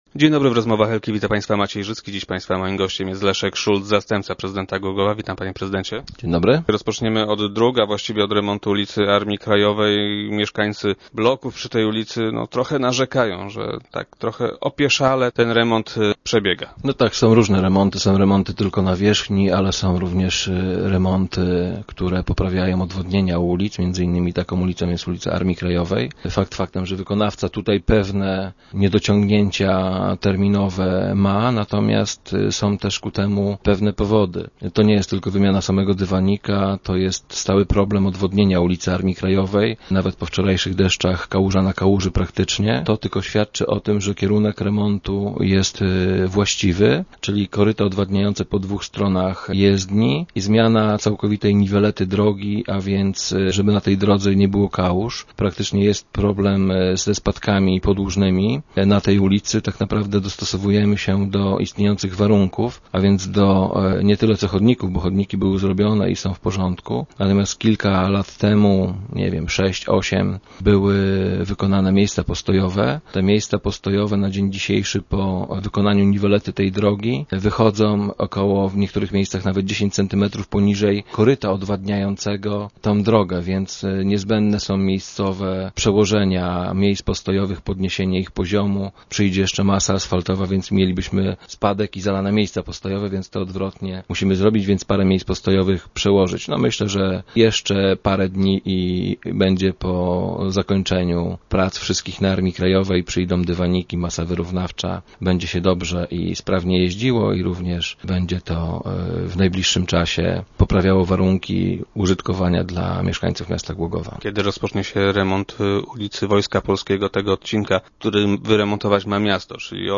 Start arrow Rozmowy Elki arrow Szulc: Musimy sobie poradzić z kałużami na Armii Krajowej